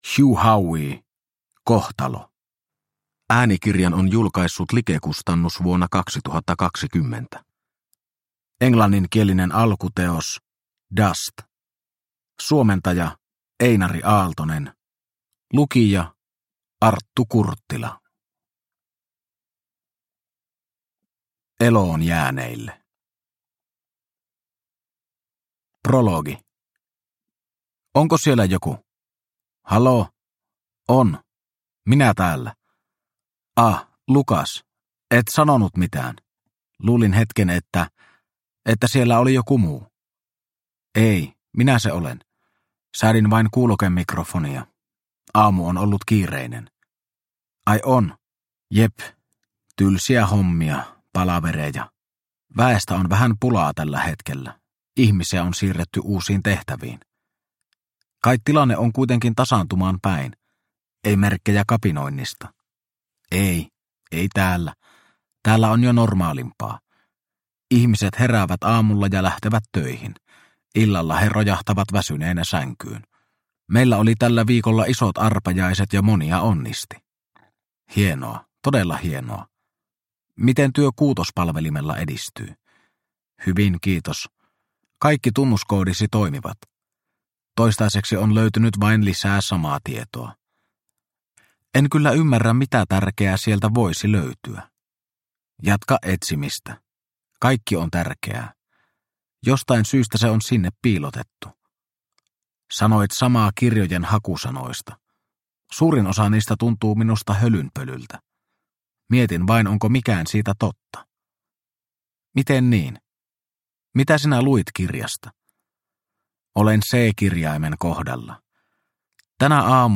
Kohtalo – Ljudbok – Laddas ner